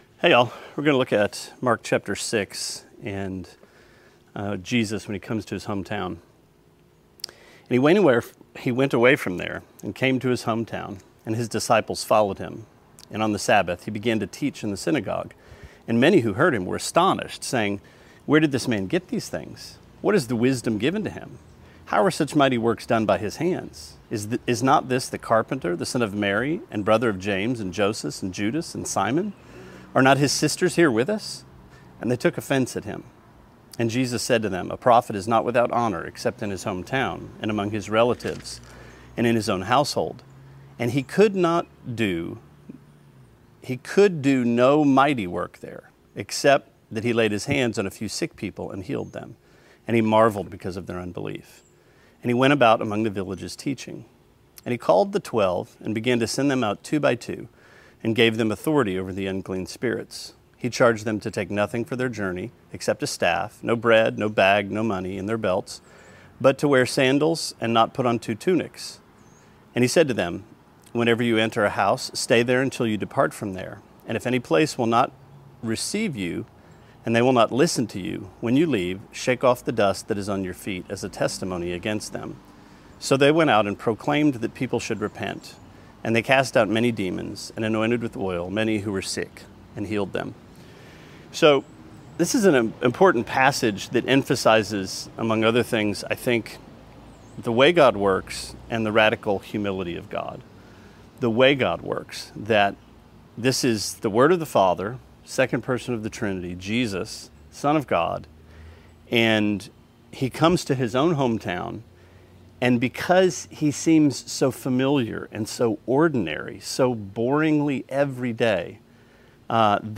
Sermonette 7/5: Mark 6:1-13: Scandal